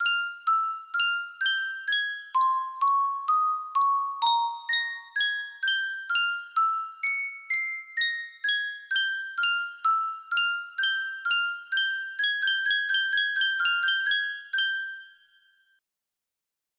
Yunsheng Custom Tune Music Box - Bach Invention No. 2 music box melody
Full range 60